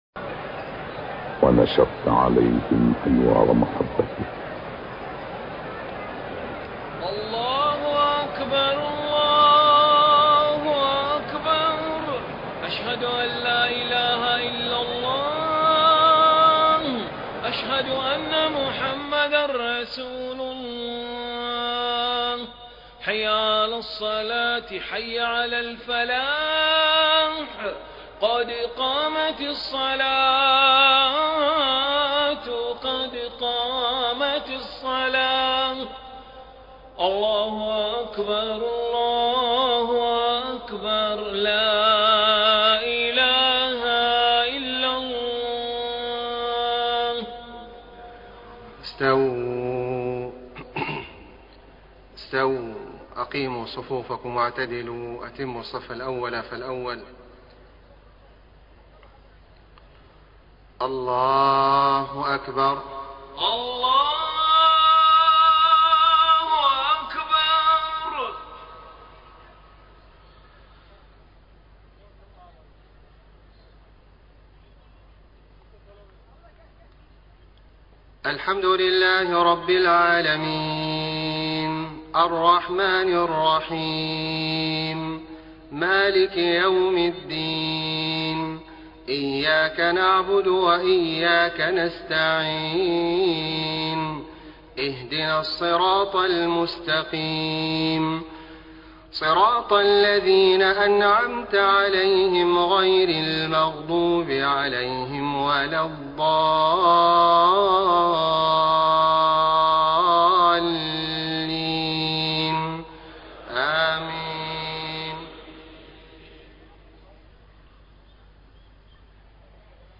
صلاة العشاء 7 - 4 - 1434هـ من سورة الأنفال > 1434 🕋 > الفروض - تلاوات الحرمين